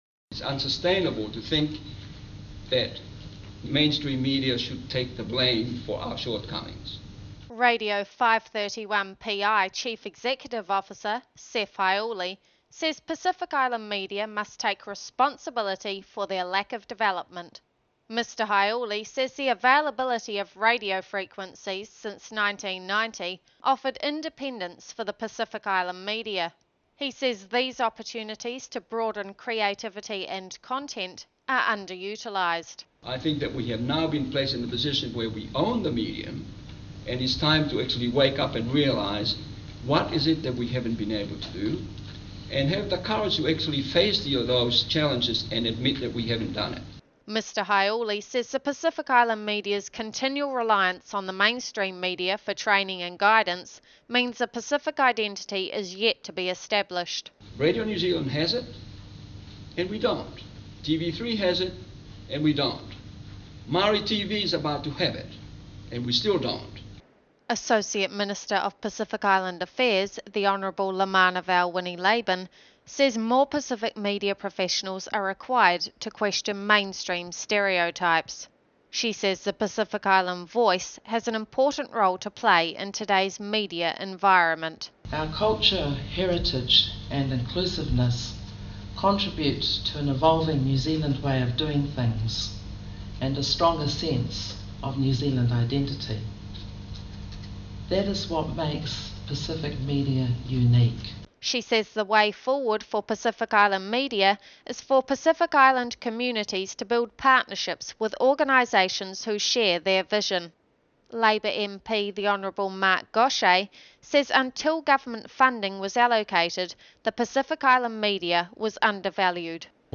• Associate Minister of Pacific Affairs Luamanuvao Winnie Laban
• Labour MP Mark Gosche
(Podcast from the Pacific Islands Media Association conference at AUT University on September 22-23, 2006).